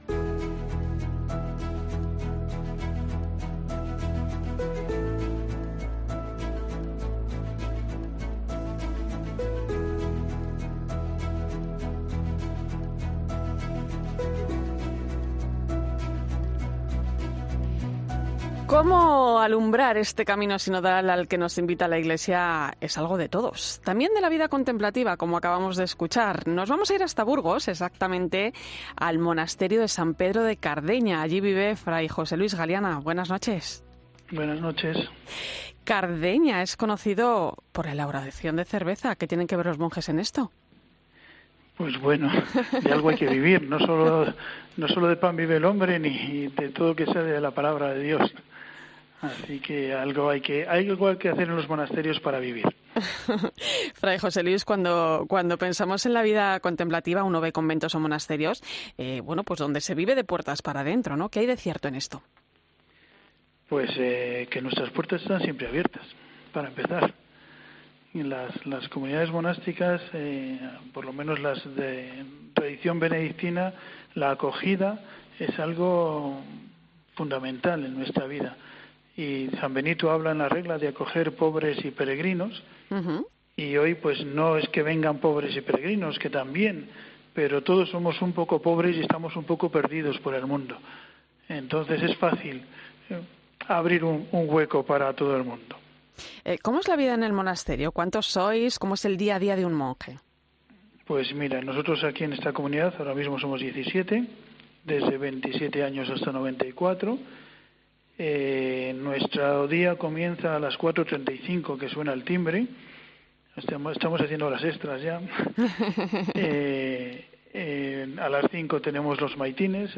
El monje trapense ha contado en 'La Linterna de la Iglesia' cómo es la vida tras los muros de la abadía de san Pedro de Cardeña, en Burgos